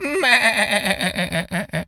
goat_baa_calm_05.wav